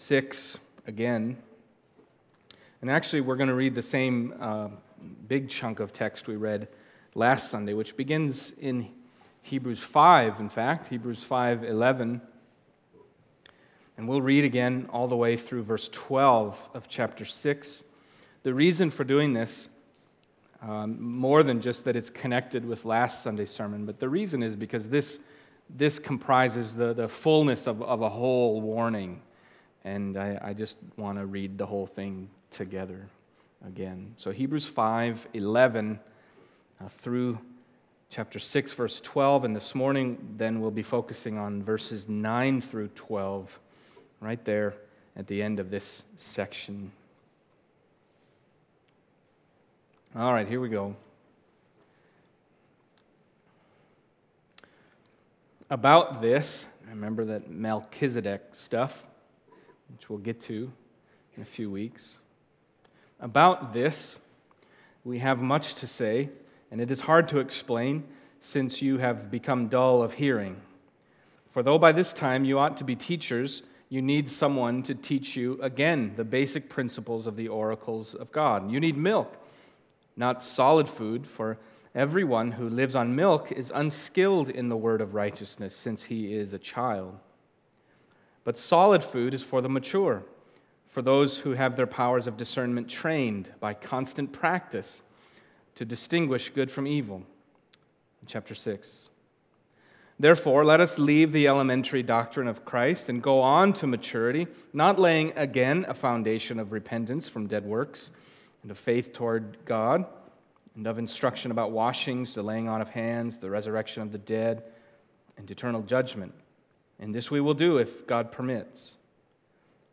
Hebrews 6:4-12 Service Type: Sunday Morning Hebrews 6:4-12